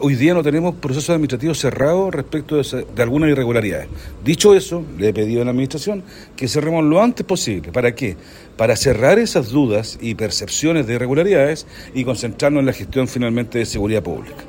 El secretario del directorio de Amzoma y concejal del Frente Amplio en Temuco, Alejandro Bizama, señaló que esos procesos se deben cerrar pronto para sólo enfocarse en seguridad.